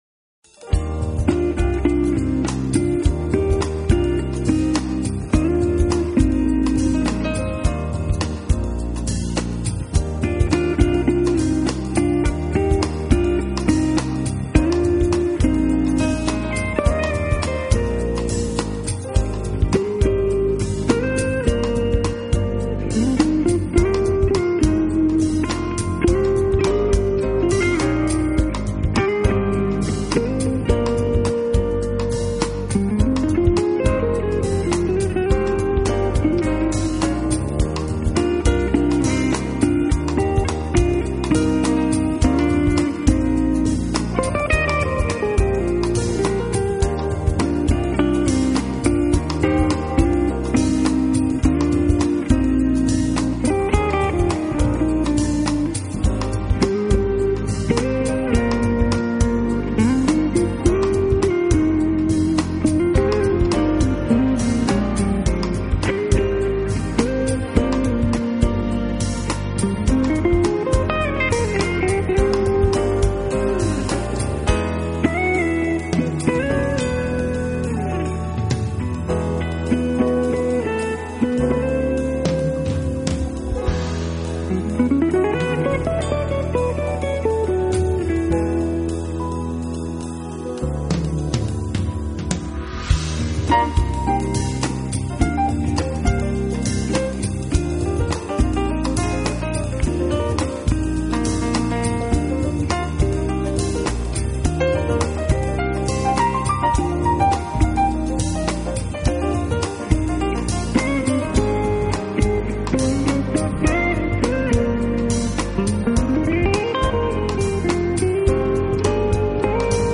爵士吉他